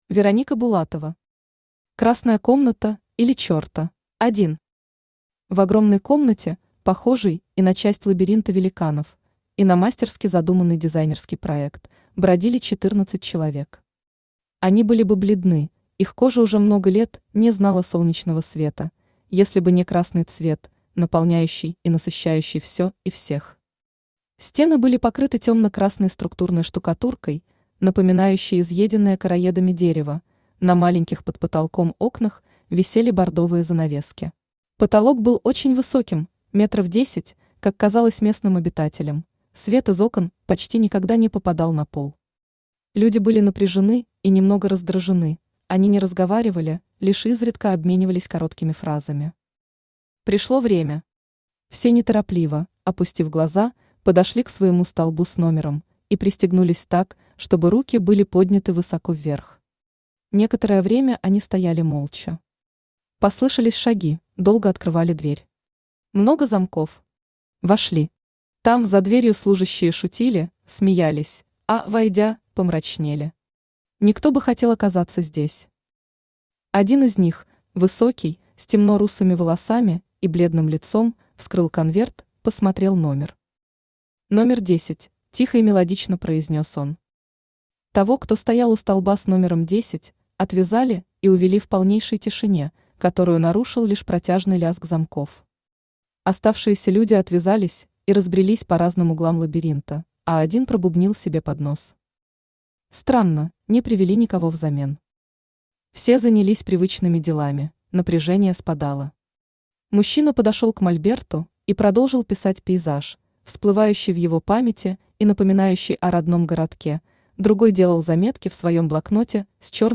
Аудиокнига Красная комната, или Черта | Библиотека аудиокниг
Aудиокнига Красная комната, или Черта Автор Вероника Александровна Булатова Читает аудиокнигу Авточтец ЛитРес.